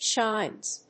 /ʃaɪnz(米国英語)/